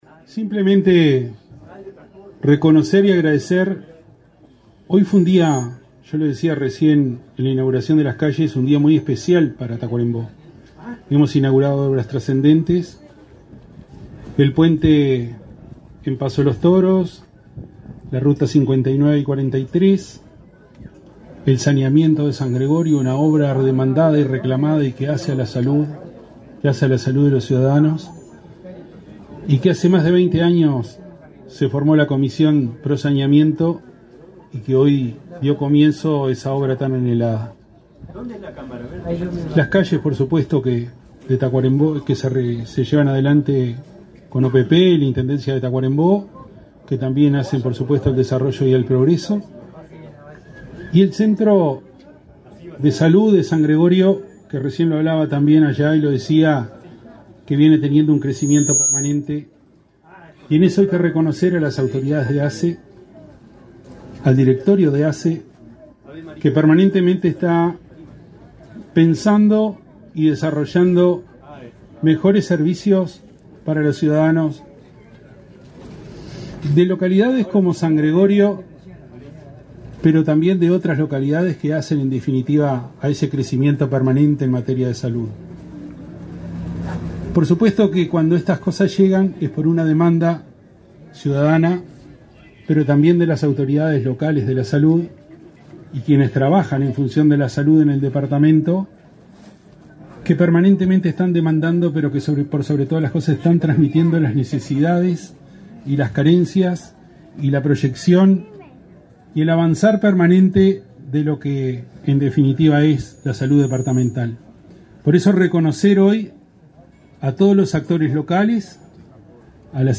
Palabras del intendente de Tacuarembó, Wilson Ezquerra
En el acto participó el intendente de Tacuarembó, Wilson Ezquerra.